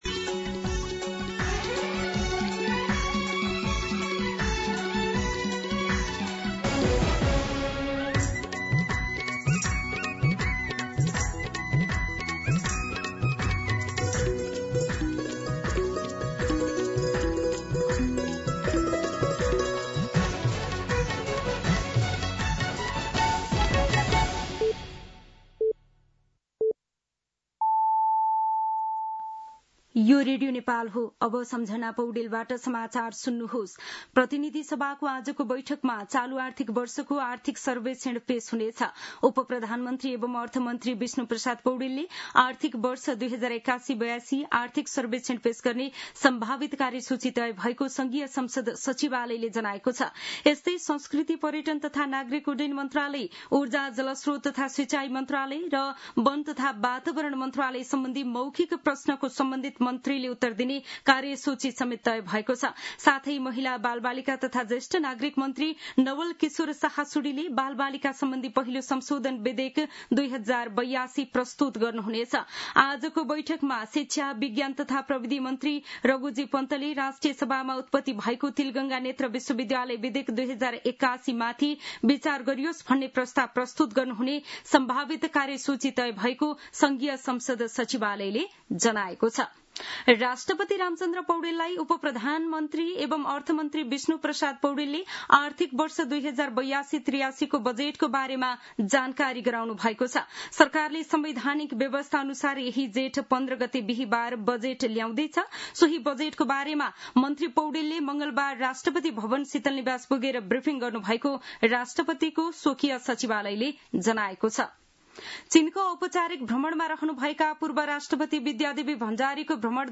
मध्यान्ह १२ बजेको नेपाली समाचार : १३ जेठ , २०८२